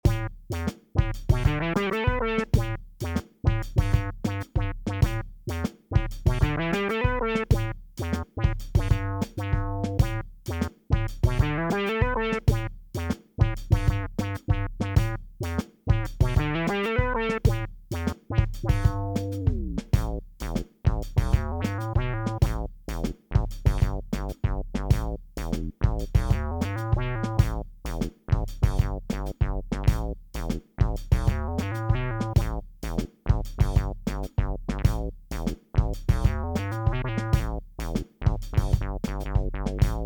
Pitch Power A few patches using pitch as a modulation source.